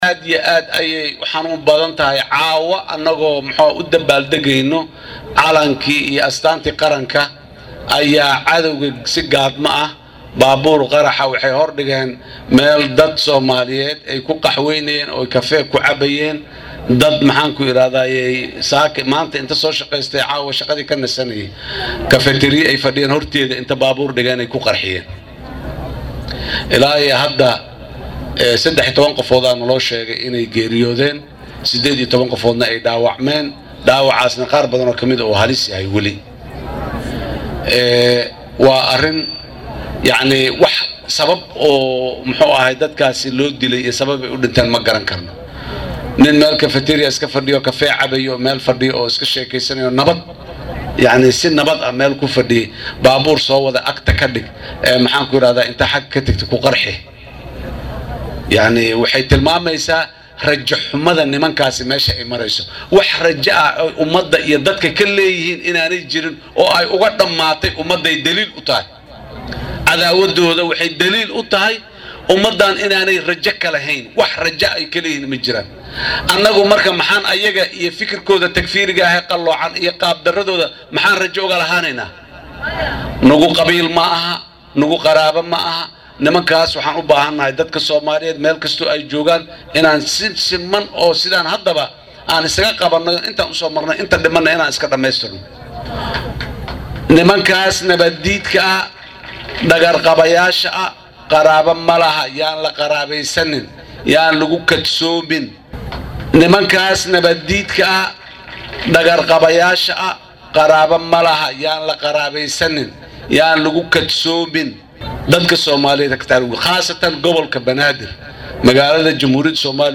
Madaxweynaha Jamhuuriyadda Federaalka ah ee Soomaaliya mudane Xasan Sheekh Maxamuud oo ka hadlayay munaasabad caawa lagu xusayay maalinta calanka oo ku beegan 12-ka October ayaa cambaareeyay falkii ka dhacay Muqdisho, isagoo qaraxaasi ku tilmaamay mid wuxuushnimo ah oo ay ka dambeeyeen kooxaha dhagar qabayaasha Shabaab.